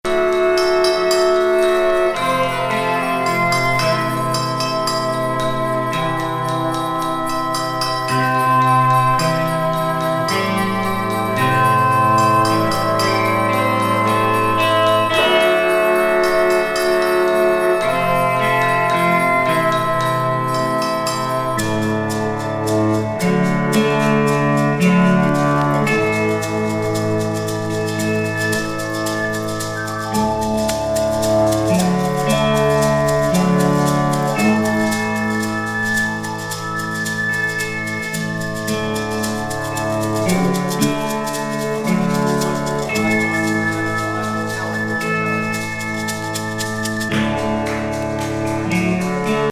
優しく